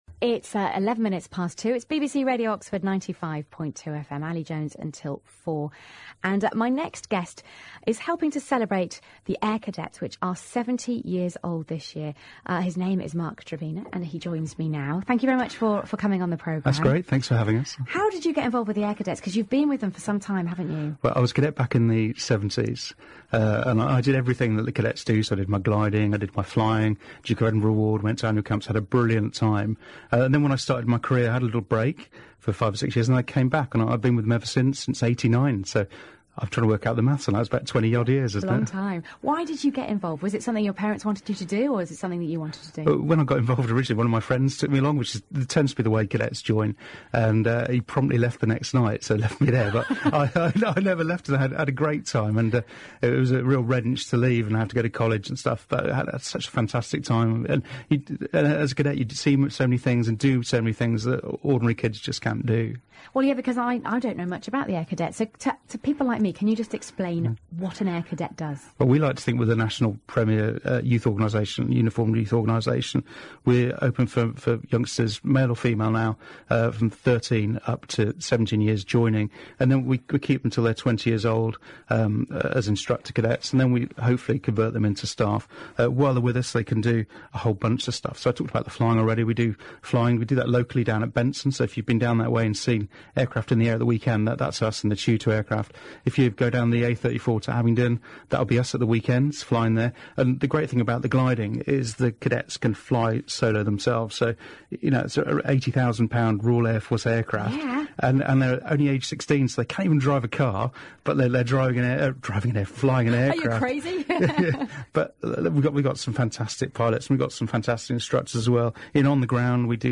Air Cadet 70th Anniversary Radio Interviews | Thames Valley Wing